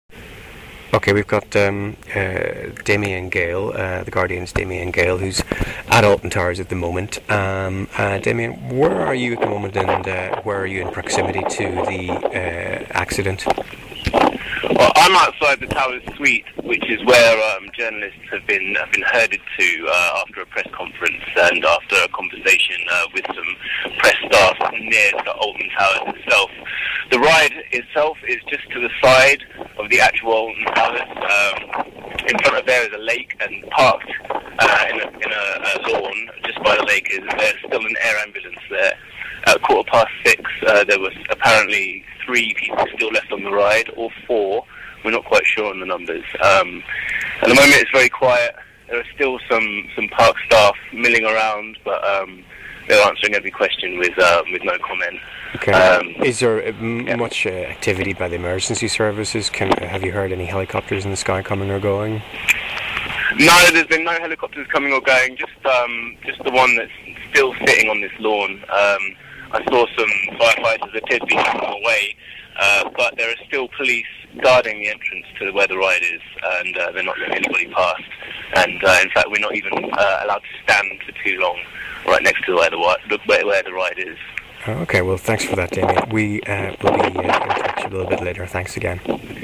on the scene at Alton Towers